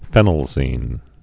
(fĕnəl-zēn)